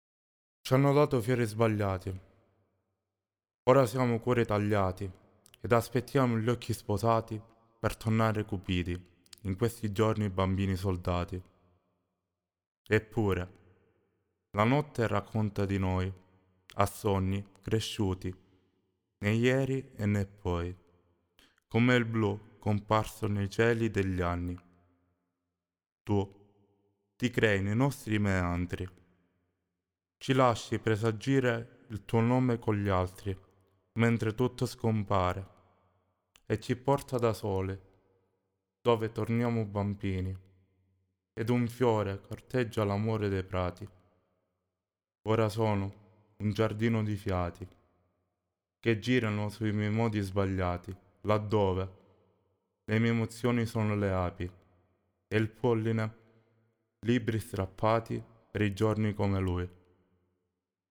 ecco i bianchi (solo voce)